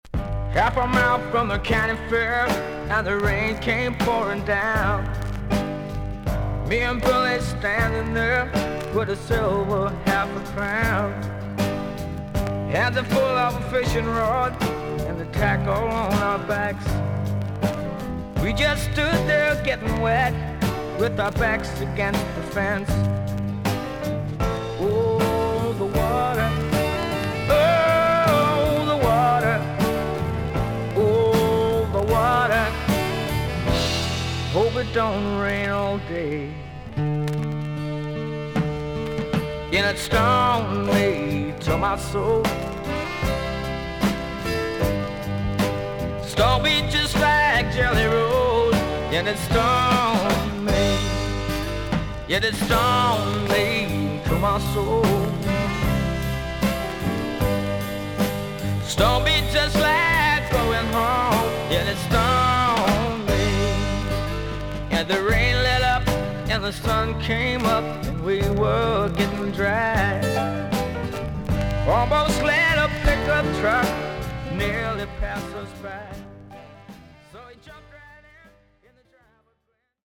少々軽いパチノイズの箇所あり。全体的に少々サーフィス・ノイズあり。